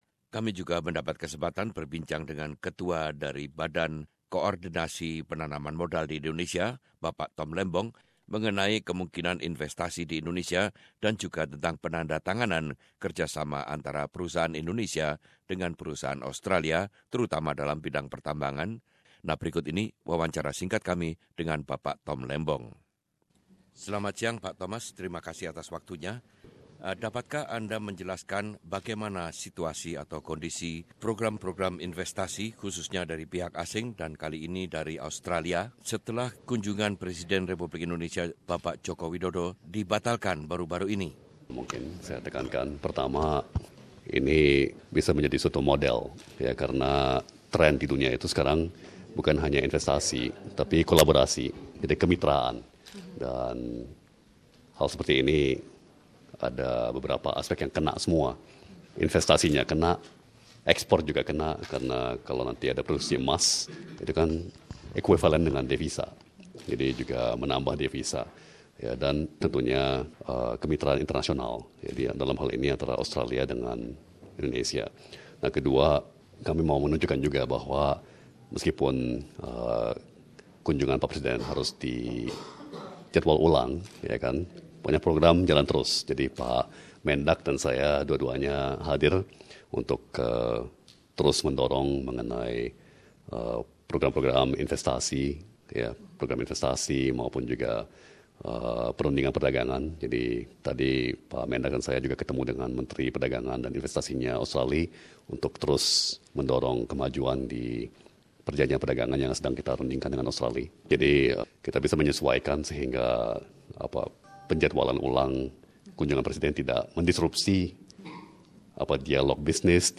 Wawancara dengan ketua Badan Koordinasi Penanaman Modal BKPM Tom Lembong mengenai penandatanganan kesepakatan antara perusahaan-perusahaan Australia dengan Indonesia dan juga tentang masa depan investasi asing di Indonesia.